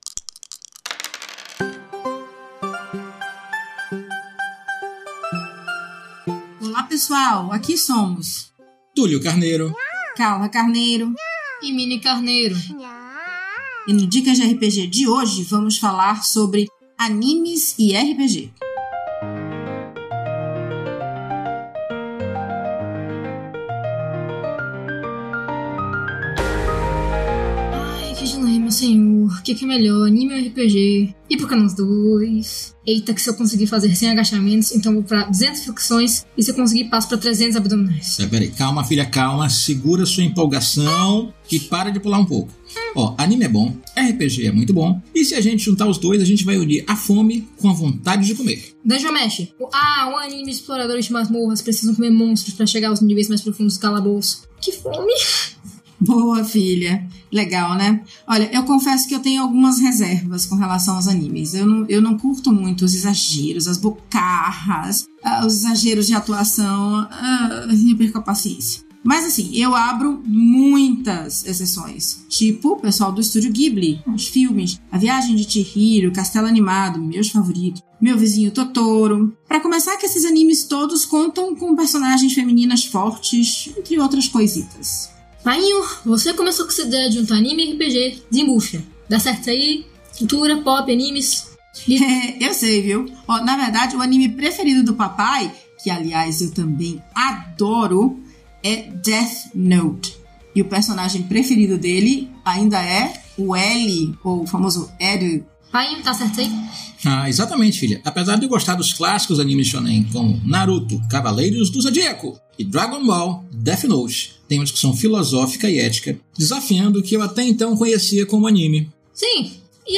O Dicas de RPG é um podcast semanal no formato de pílula que todo domingo vai chegar no seu feed.
Músicas: Music by from Pixabay